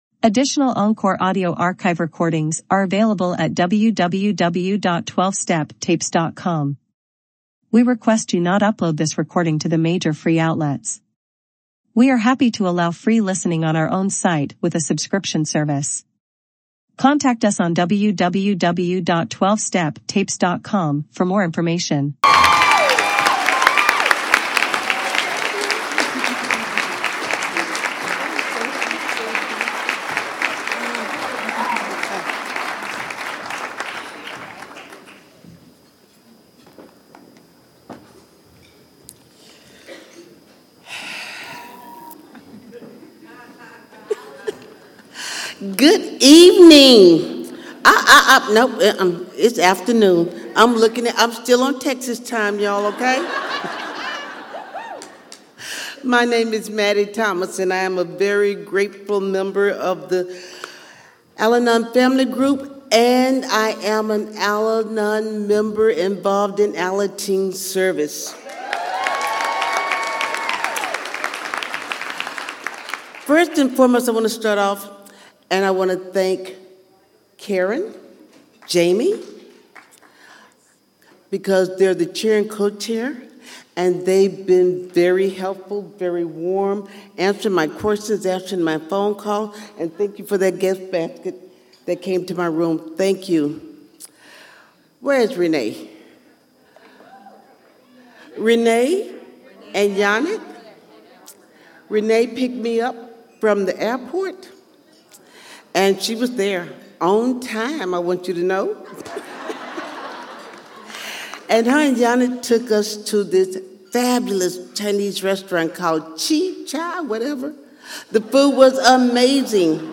San Fernando Valley AA Convention 2024 - Let Go and Let God